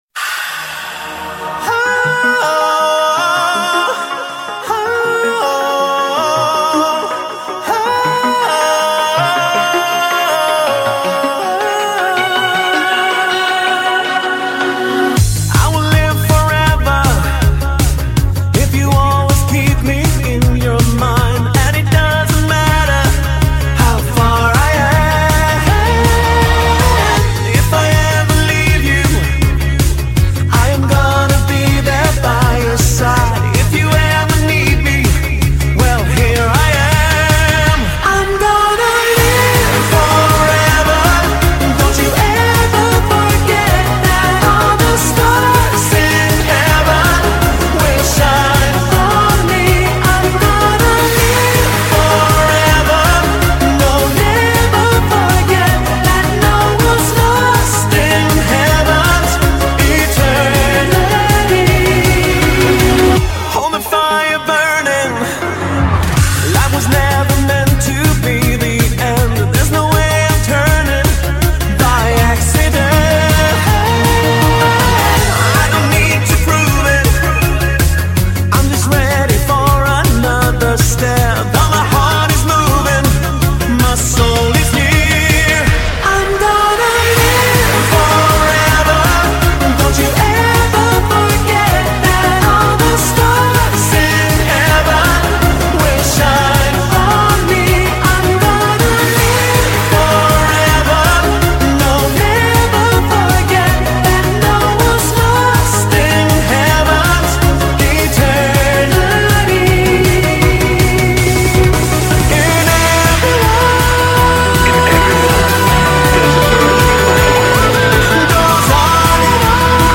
jest dyskotekowa i pobudzająca do życia